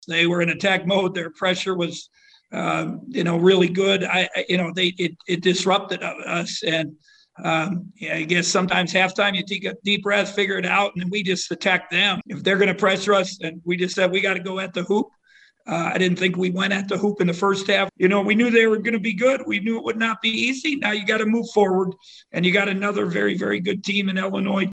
Kansas State Coach Bruce Weber said Arkansas came out and attacked them.
11-23-bruce-weber.mp3